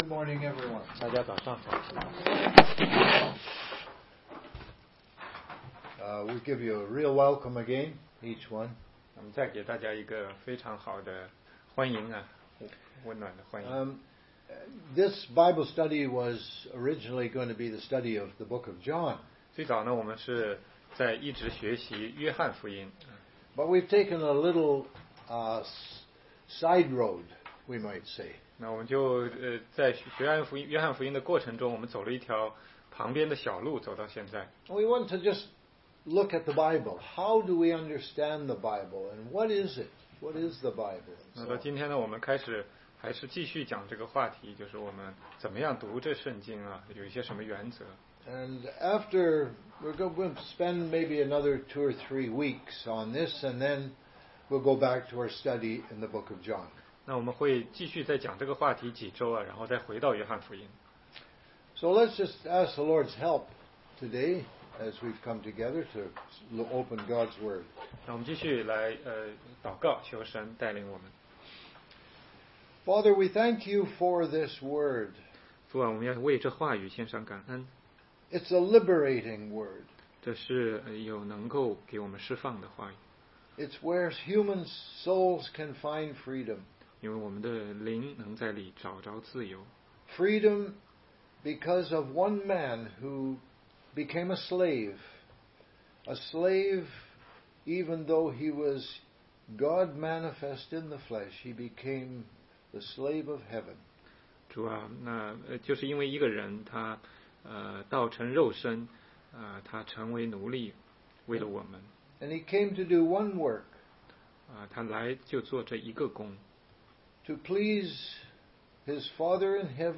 16街讲道录音 - 怎样才能读懂圣经系列之四